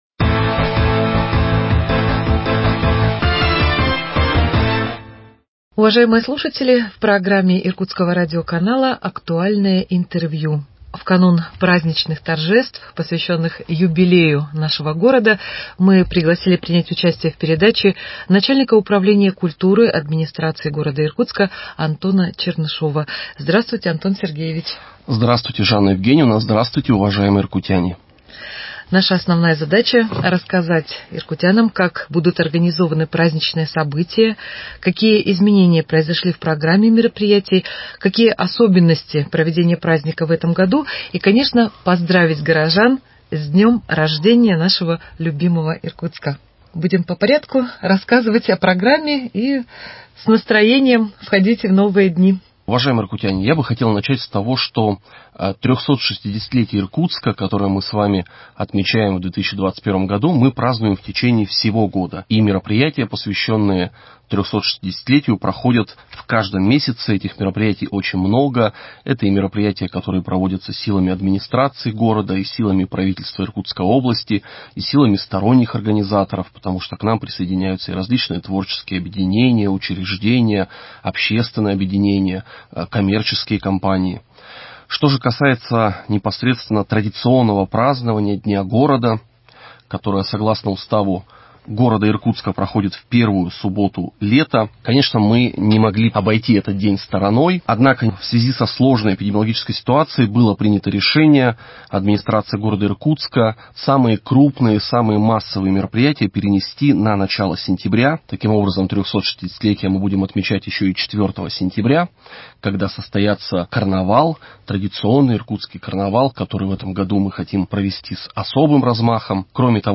Актуальное интервью: Программа мероприятий на День города 03.06.2021